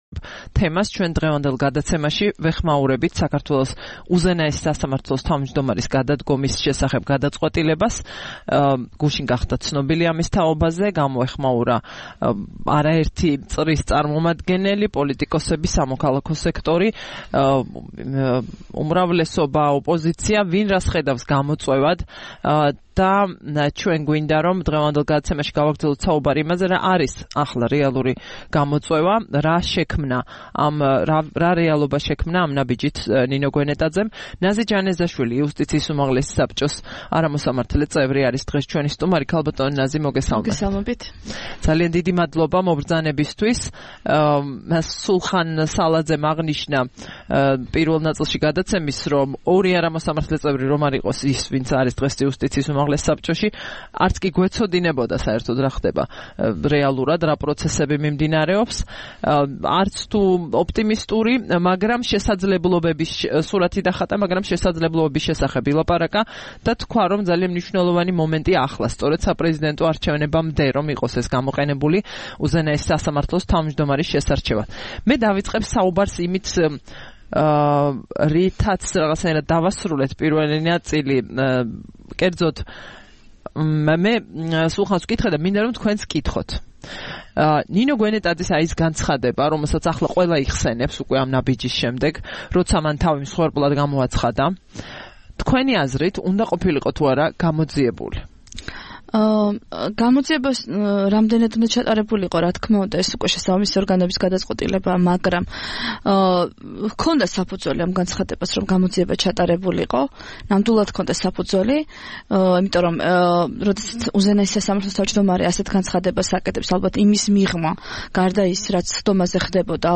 3 აგვისტოს რადიო თავისუფლების "დილის საუბრების" სტუმარი იყო ნაზი ჯანეზაშვილი, იუსტიციის უმაღლესი საბჭოს წევრი.